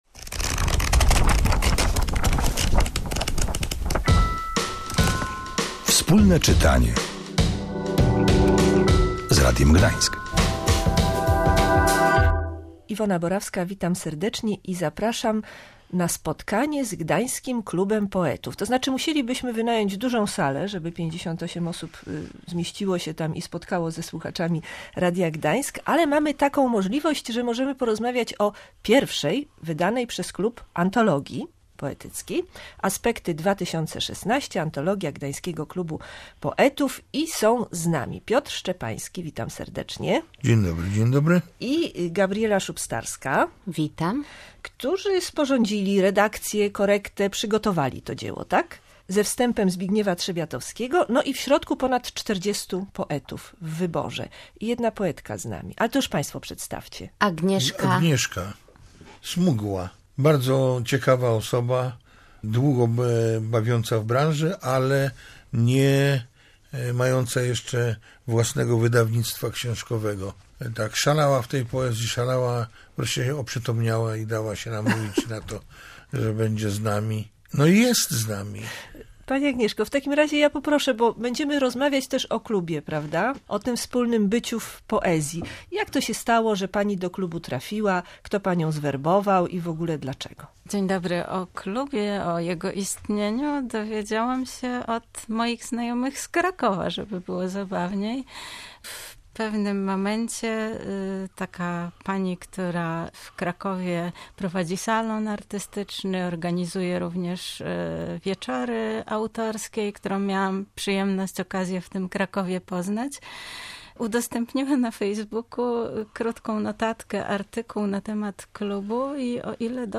W studiu pojawili się poeci i redaktorzy tomu